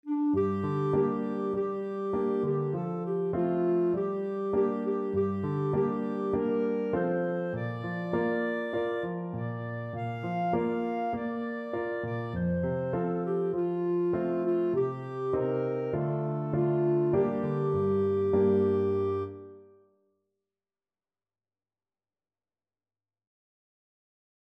Clarinet
2/4 (View more 2/4 Music)
Moderato
G minor (Sounding Pitch) A minor (Clarinet in Bb) (View more G minor Music for Clarinet )
Traditional (View more Traditional Clarinet Music)